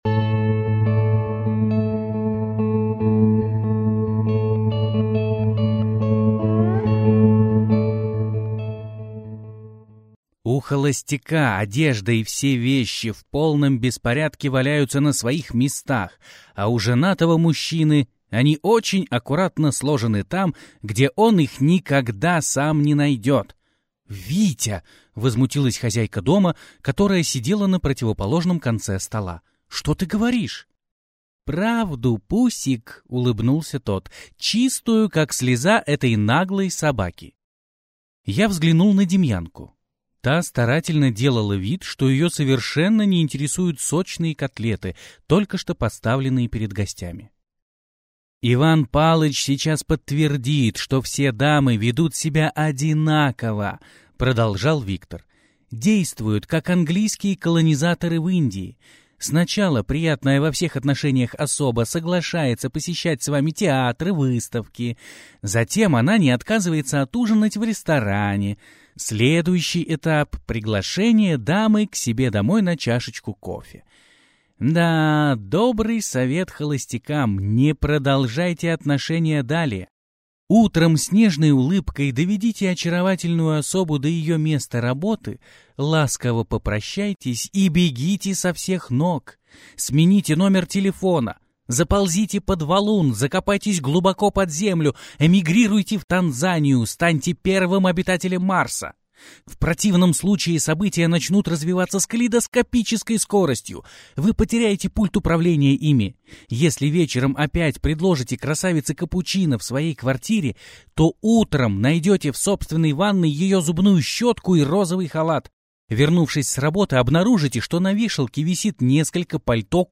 Аудиокнига Гнездо перелетного сфинкса - купить, скачать и слушать онлайн | КнигоПоиск